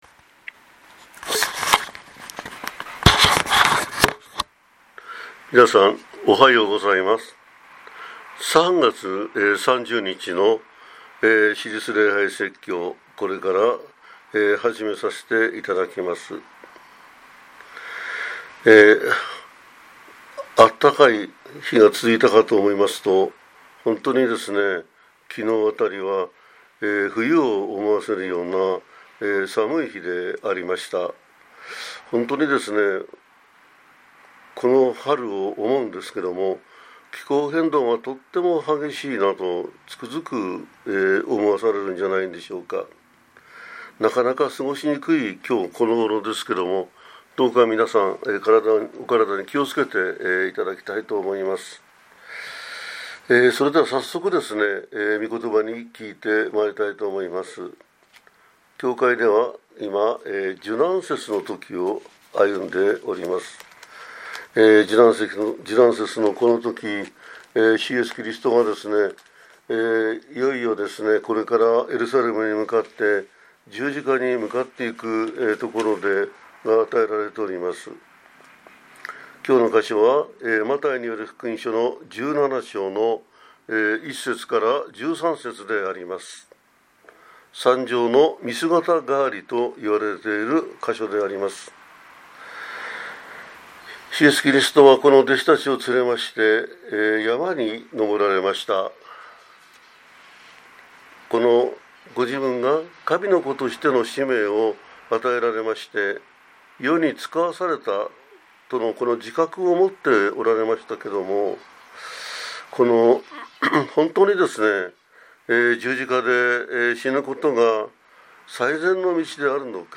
説教 十字架を負って歩む